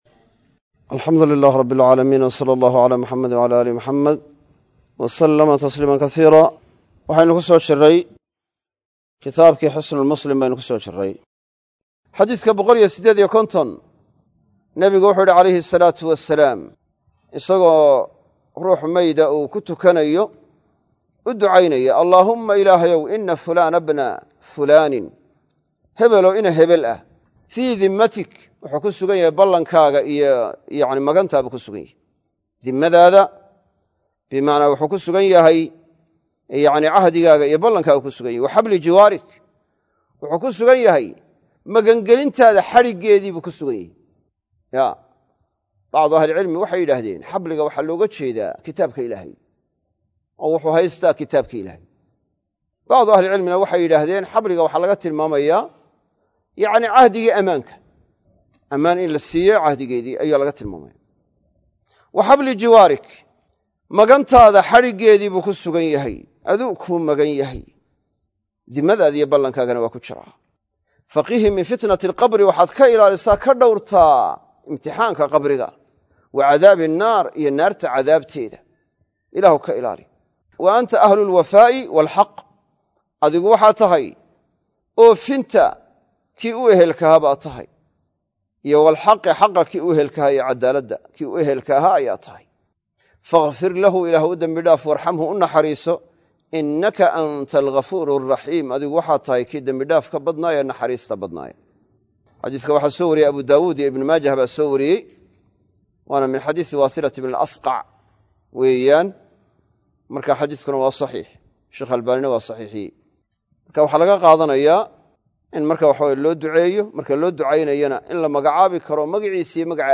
Xisnul-Muslim- Darsiga 16aad